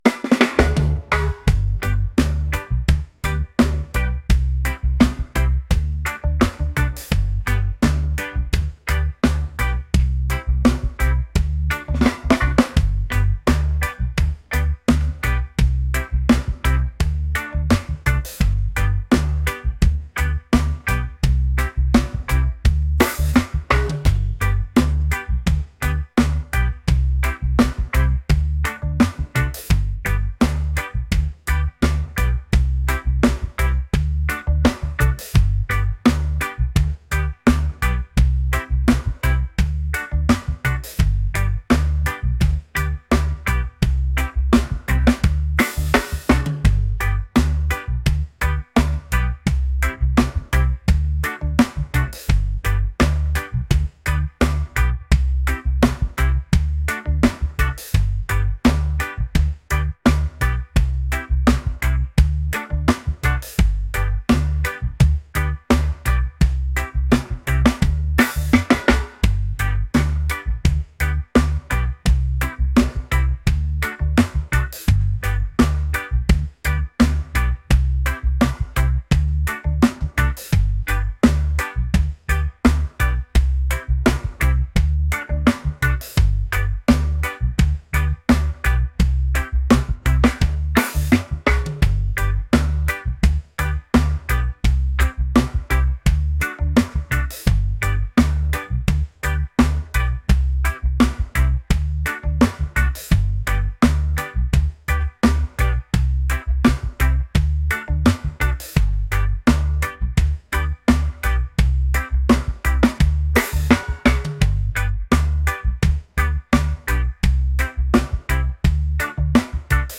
groovy | upbeat | reggae